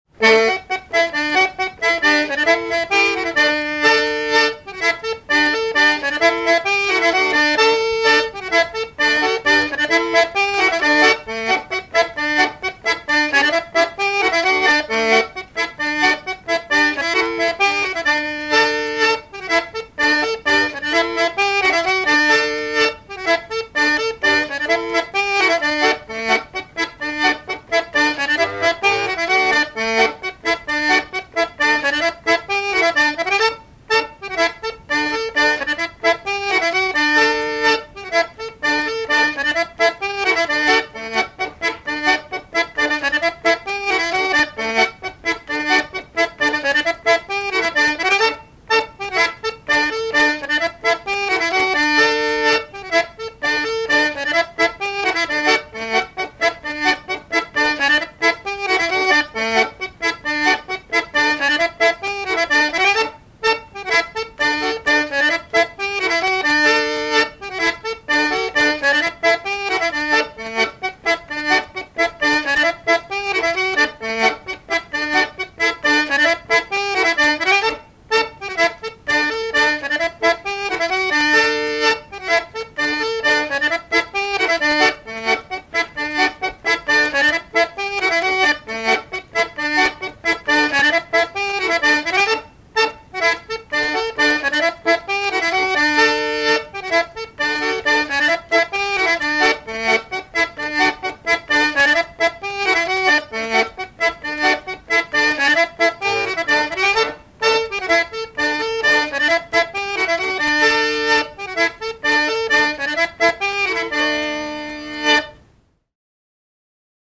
danse : angoise, maristingo ;
Répertoire d'airs à danser
Pièce musicale inédite